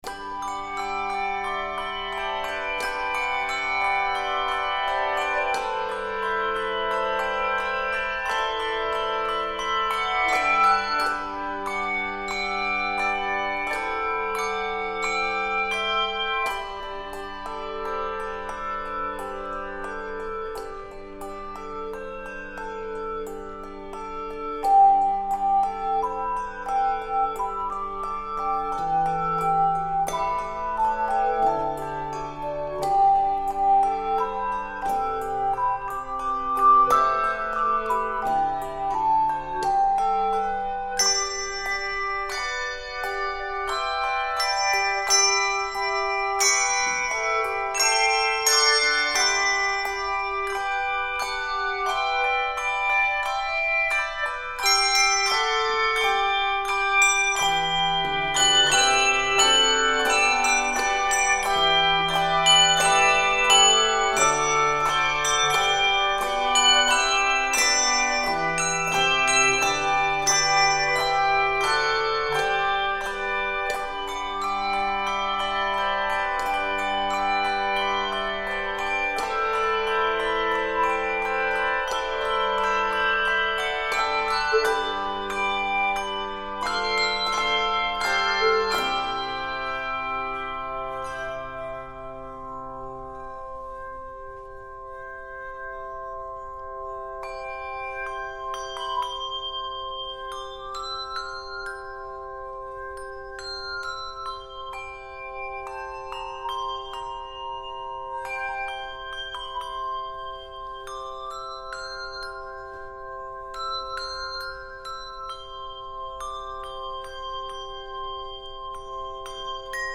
medley
accompanied only by singing bells
Keys of G Major and C Major.